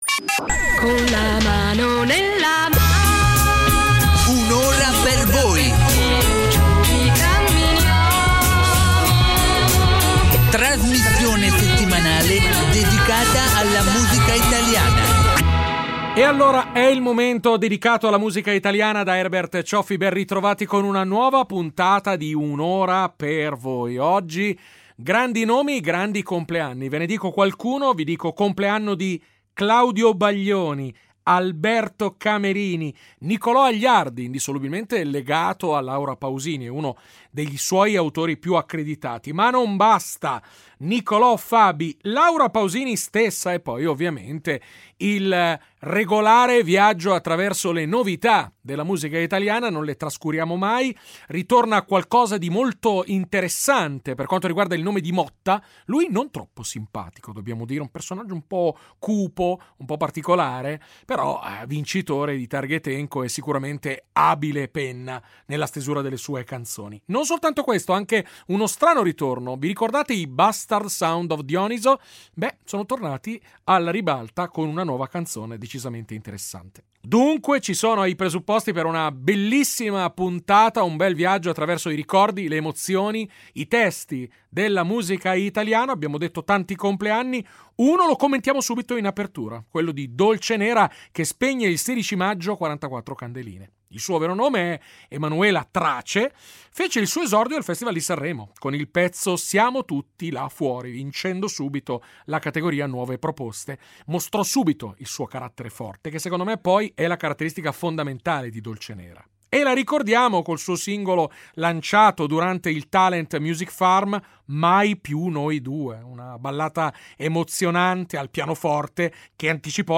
con la sua voce graffiata e di carattere